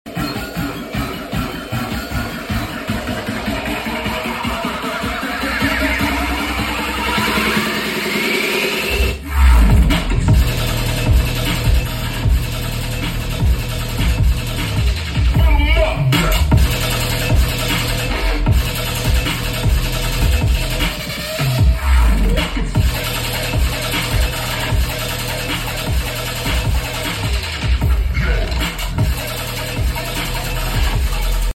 Duo Jbl PartyBox 710 Stereo sound effects free download
Duo Jbl PartyBox 710 Stereo 70%vol Bass Full Eq The Best!!